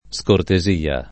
scortesia [ S korte @& a ] s. f.